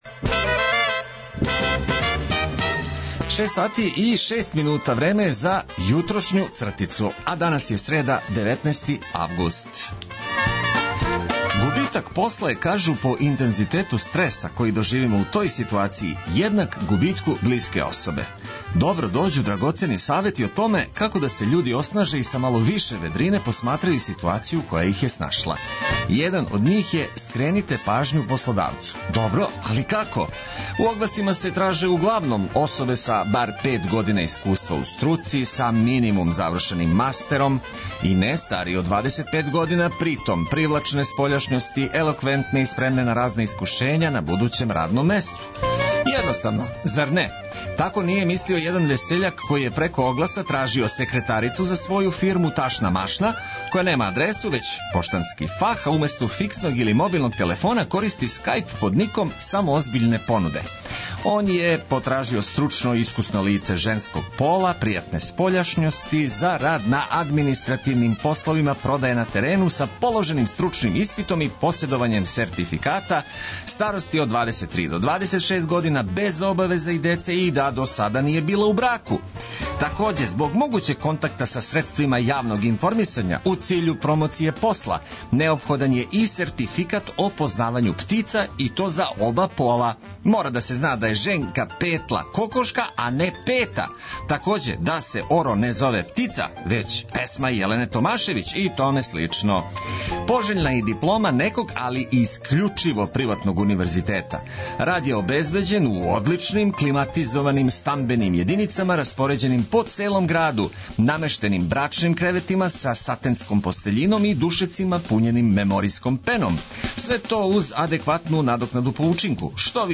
Корисне информације уз обиље добре музике, то је одлика заједничког започињања новог дана, јединог који претходи четвртку.